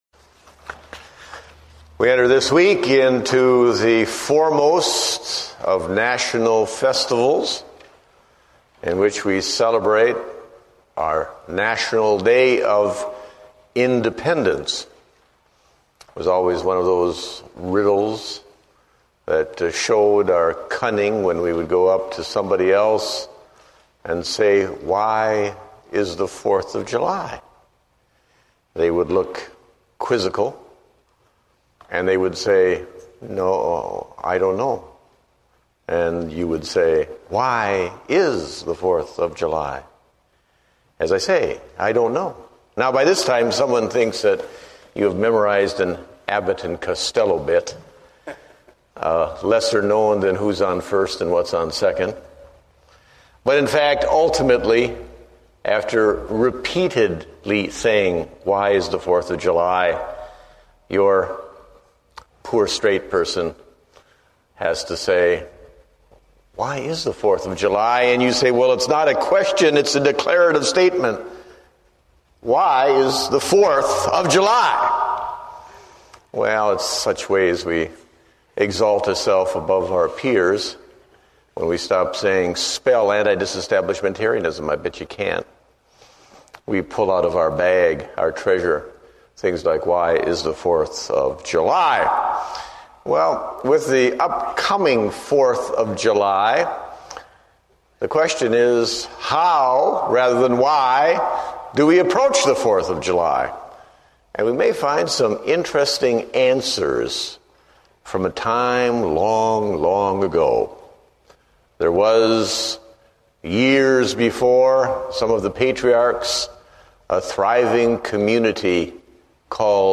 Date: June 29, 2008 (Evening Service)